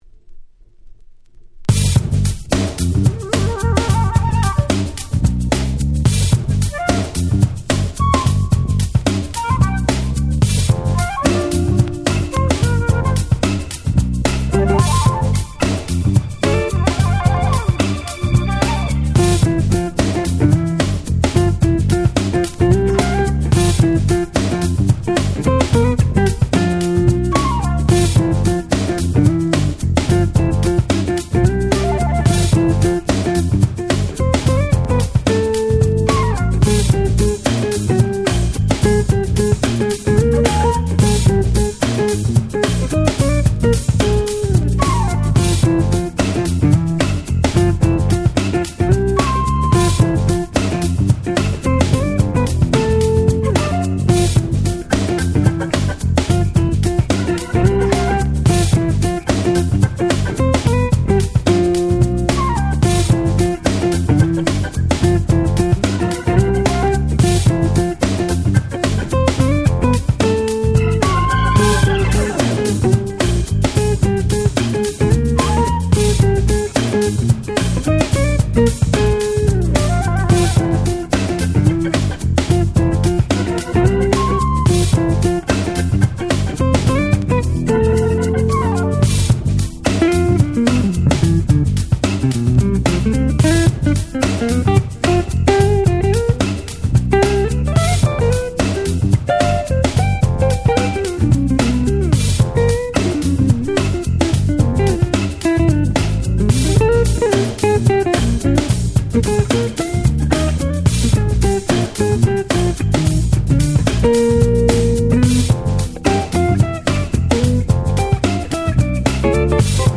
93' Nice Acid Jazz / UK Soul !!